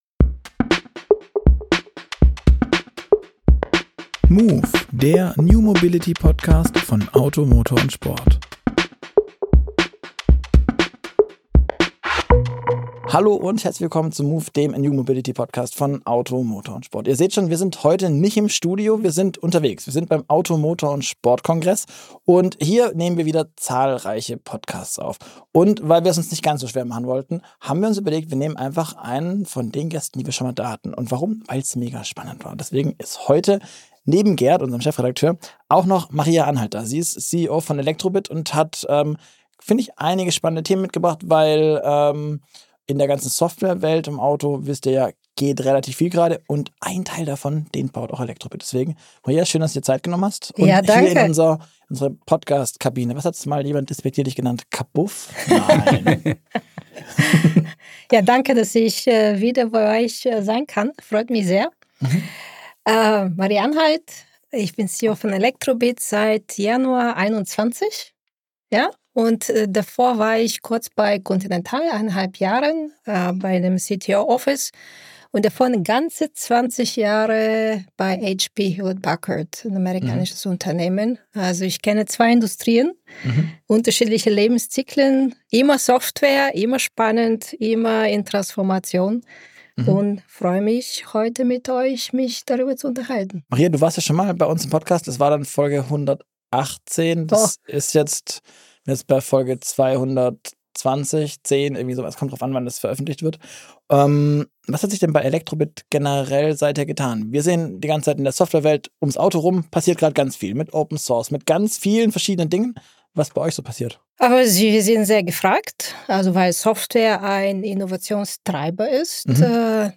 Heute mal Studio-Flair auf Reisen: Wir sitzen auf dem Auto Motor und Sport Kongress – und haben uns einen Gast geschnappt, bei dem es beim letzten Mal schon ordentlich geknistert hat.